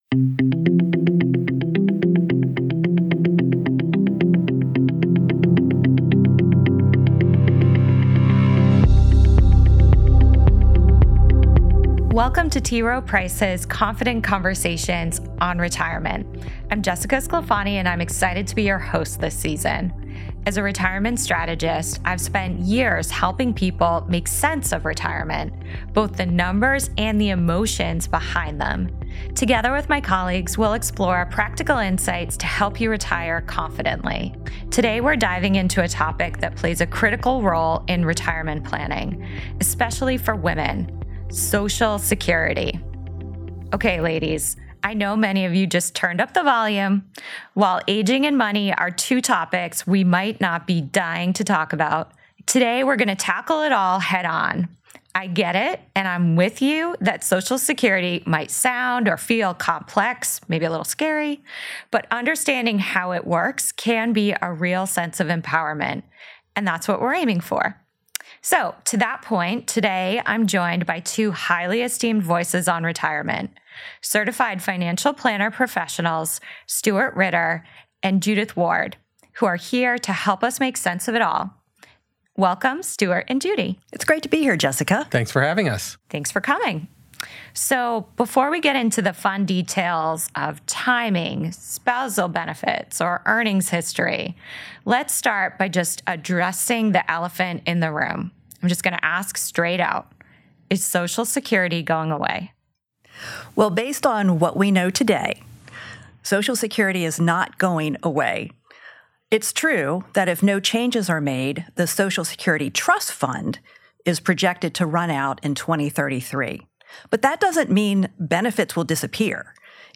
for a practical and encouraging discussion on how women—and their spouse if they have one—can make informed, confident Social Security decisions.